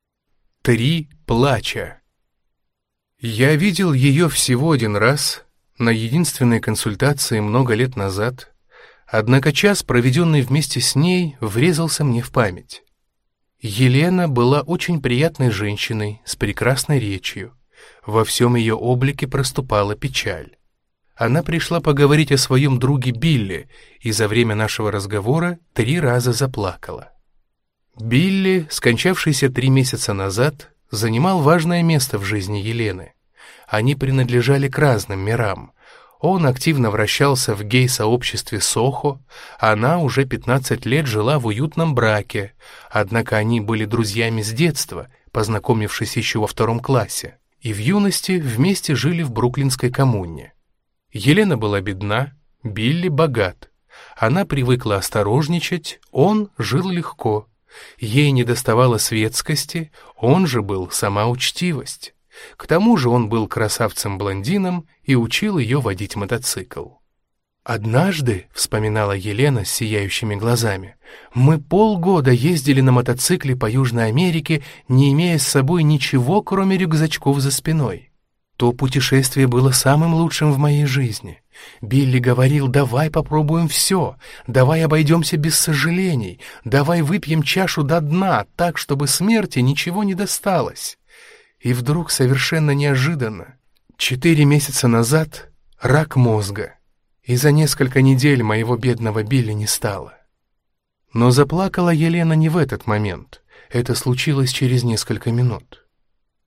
Аудиокнига Три плача | Библиотека аудиокниг